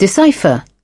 Transcription and pronunciation of the word "decipher" in British and American variants.